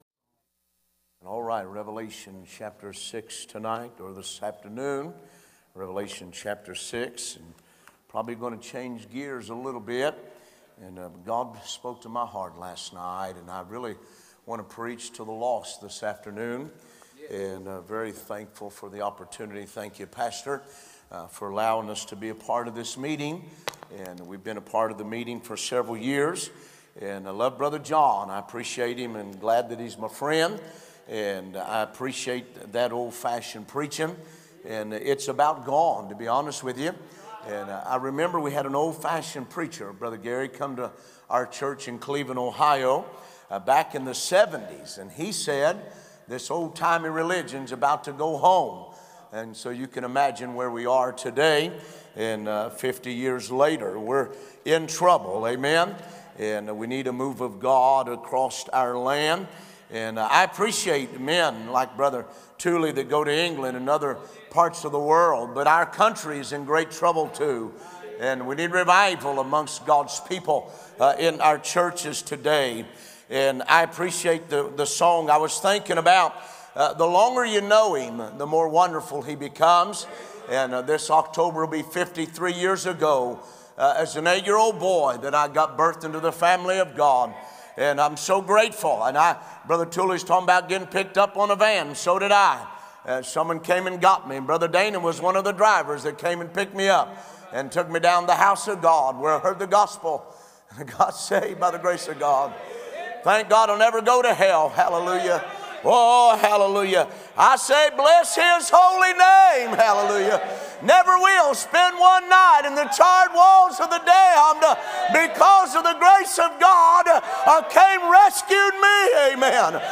A message from the series "2025 Spring Jubilee."